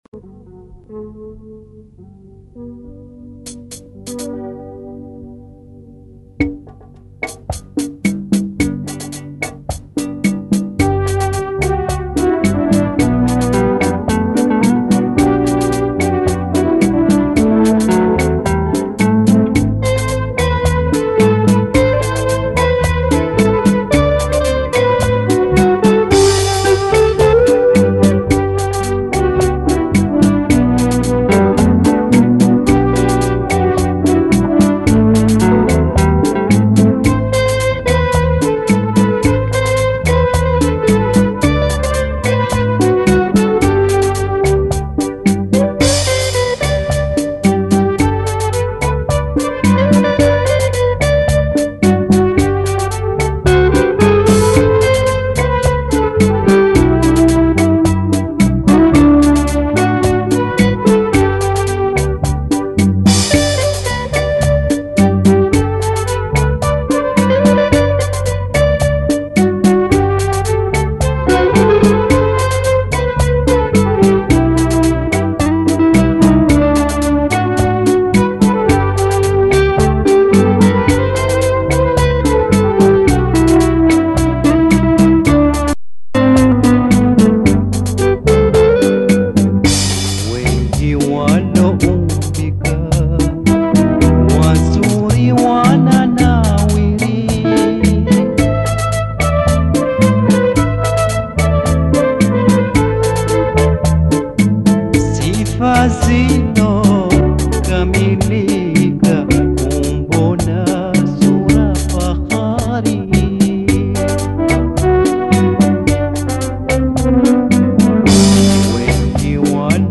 Taarab